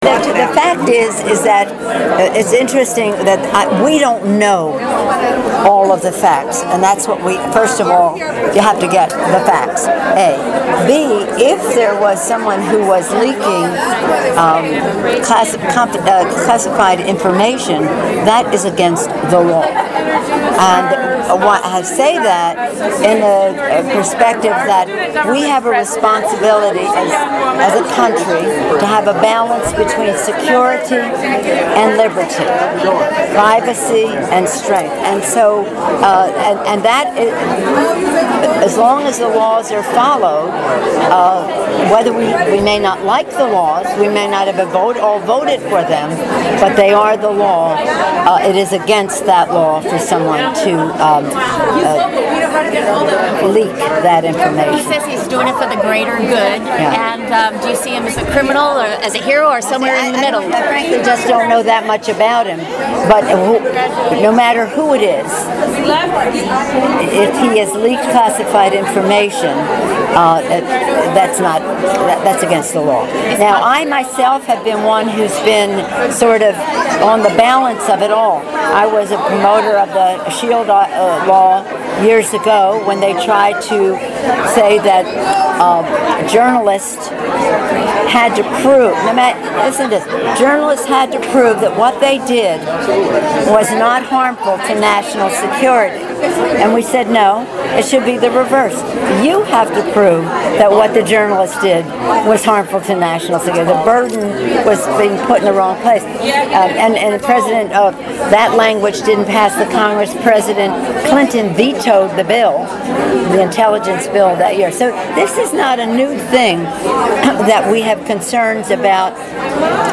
Hear audiotape of Pelosi remarks on leaks
The former House Speaker called for a balance between “liberty and security”  in a press conference following a forum celebrating the 50th anniversary of the Equal Pay Act.